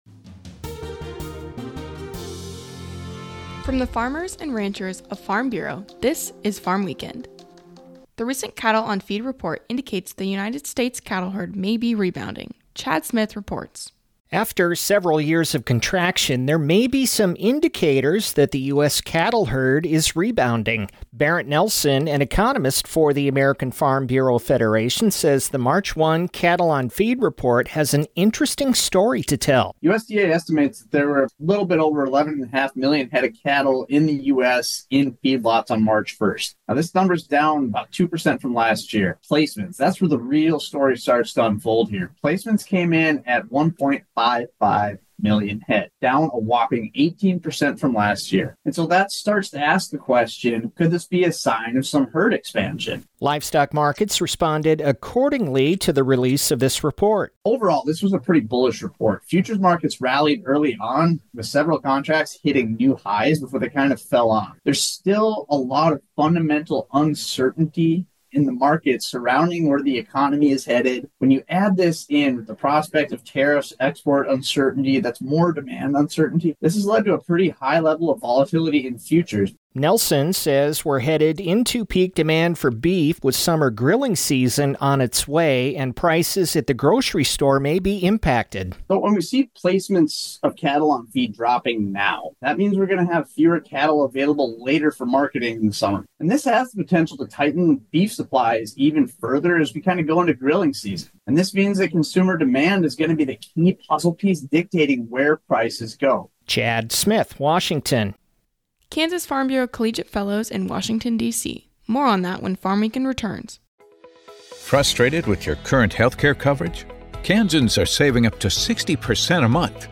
A 5-minute radio program featuring a recap of the week's agriculture-related news and commentary.